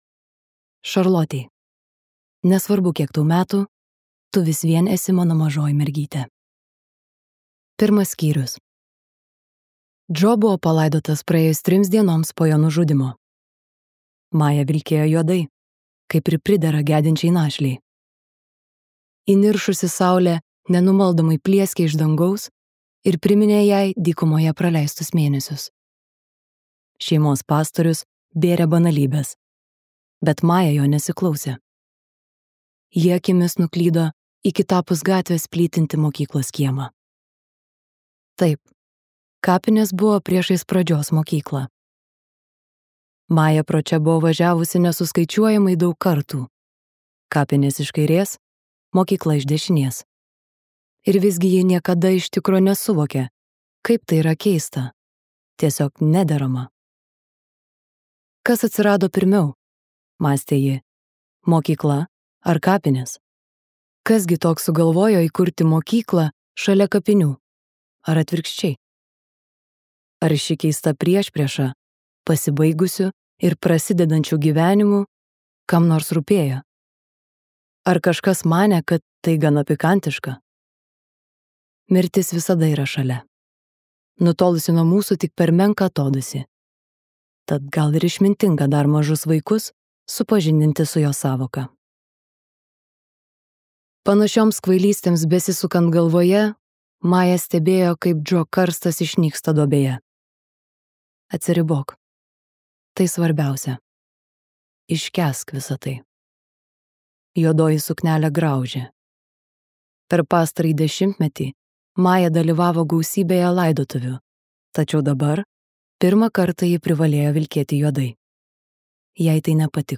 Apgauk mane kartą | Audioknygos | baltos lankos